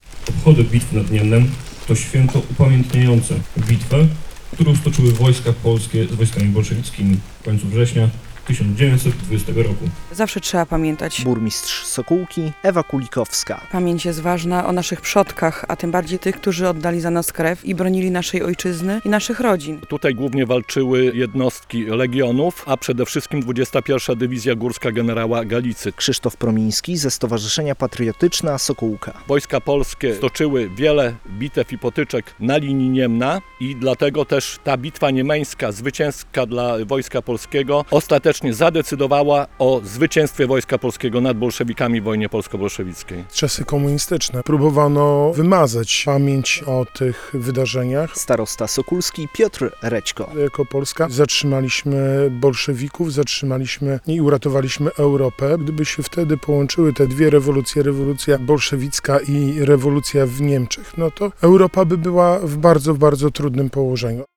Mieszkańcy Sokółki oddali hołd uczestnikom Bitwy Niemeńskiej - relacja
W centrum miasta, przed pomnikiem marszałka Józefa Piłsudskiego były okolicznościowe przemówienia i występ orkiestry dętej, a wcześniej na grobach poległych na cmentarzu w Sokółce złożono kwiaty i zapalono znicze: